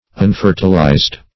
Meaning of unfertilised. unfertilised synonyms, pronunciation, spelling and more from Free Dictionary.
unfertilised.mp3